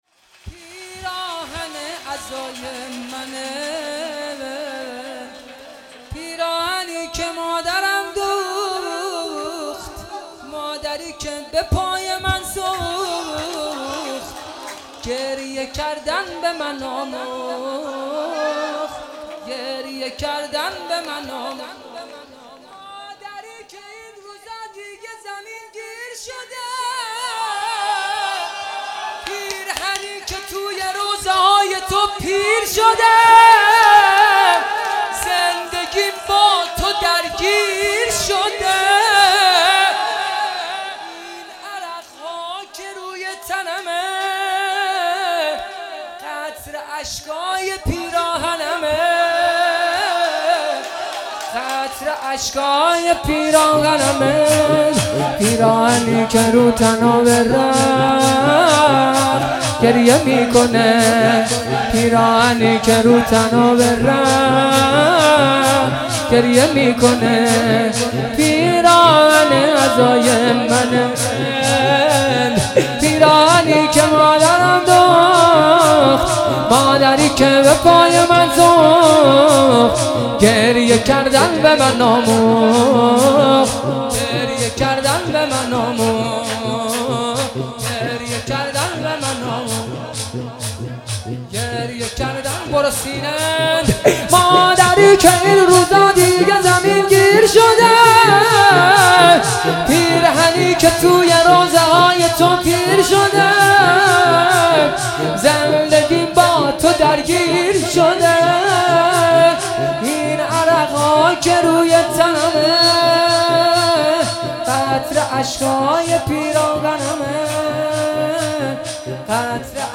مراسم شب ۲ صفر ۱۳۹۷
دانلود شور